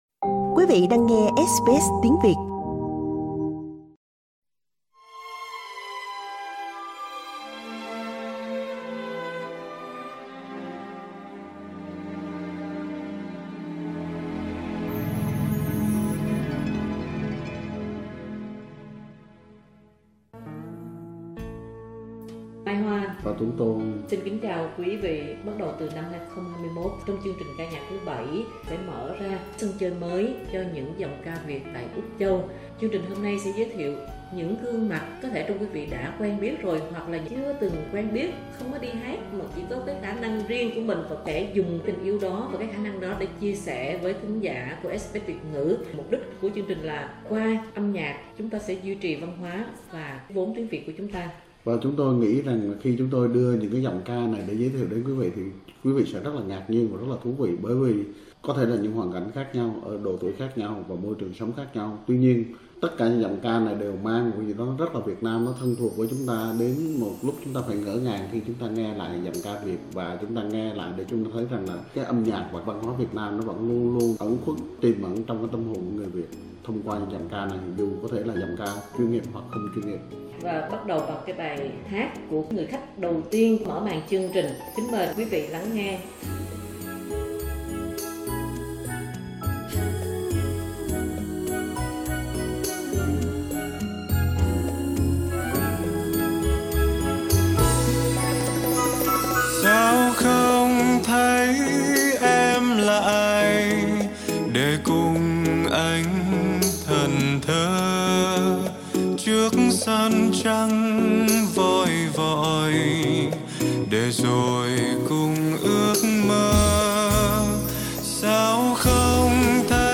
Anh có giọng ca rất đẹp, đầy nam tính và đầy nội lực. Anh có những cú lên cao đầy đặn chạm đúng cung bậc tình cảm và những cú bỏ nhỏ chết người.